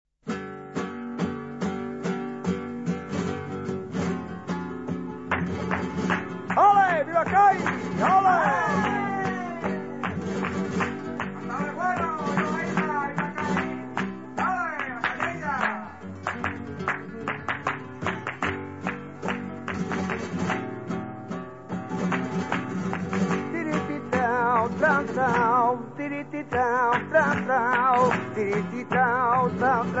Alegrias de Cadiz